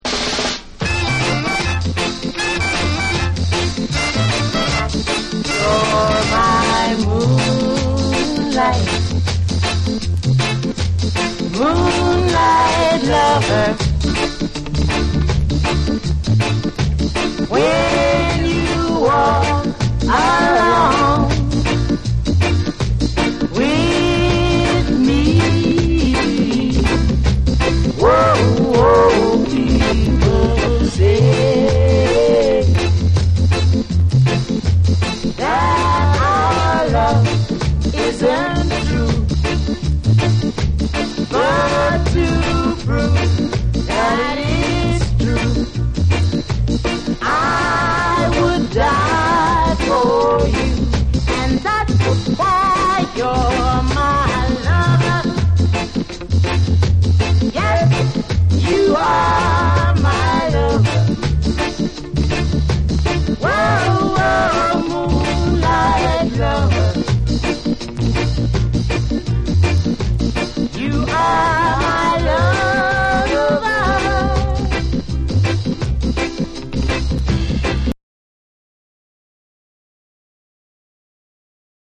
ロックステディ〜初期レゲエの名曲を濃縮した、まさにUKレゲエ入門にも最適な1枚！
REGGAE & DUB